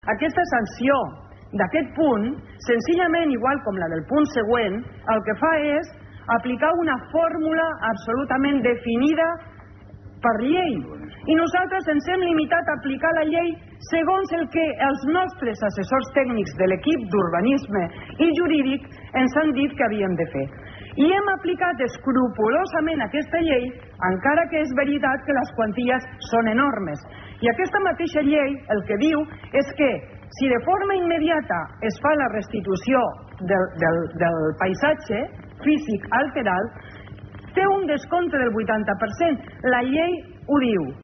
Finalment, ahir al vespre va passar pel ple extraordinari el tens debat sobre l’aprovació de les sancions als càmpings.